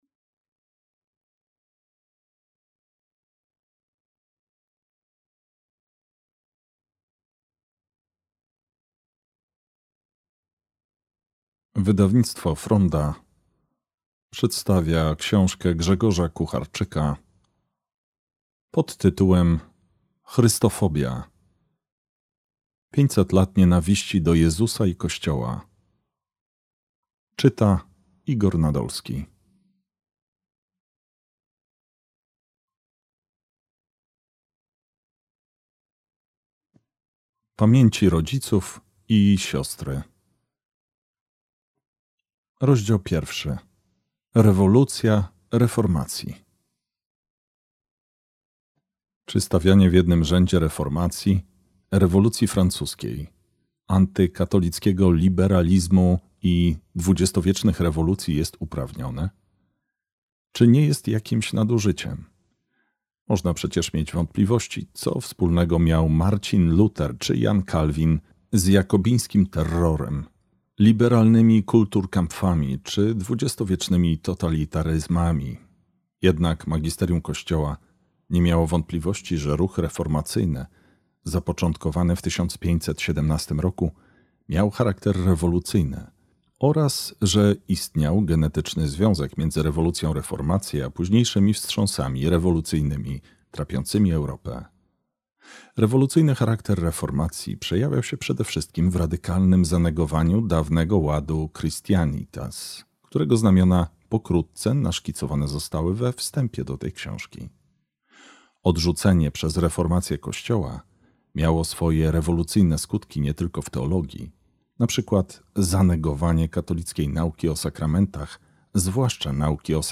Chrystofobia – Audiobook